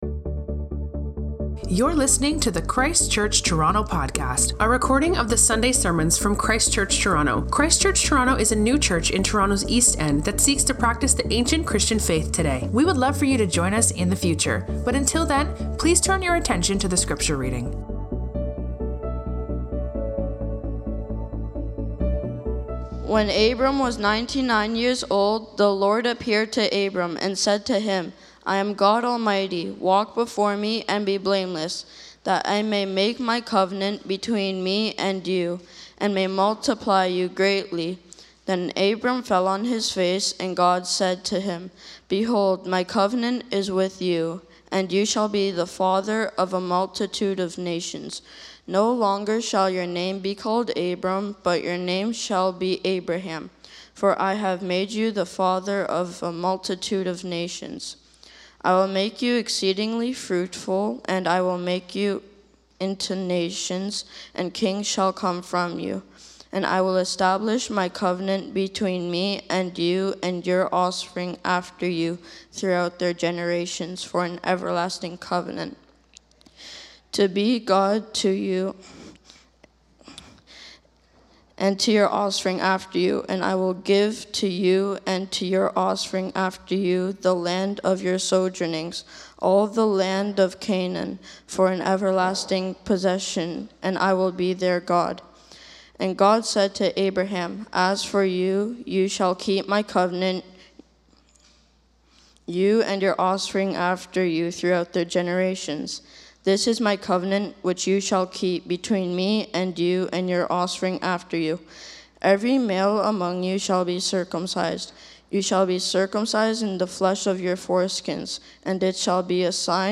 This podcast features sermons preached at Christ Church Toronto.